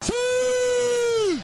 крики